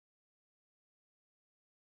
100% file RIFF (little-endian) data, WAVE audio, Microsoft PCM, 16 bit, mono 11025 Hz default
SILENCE2.WAV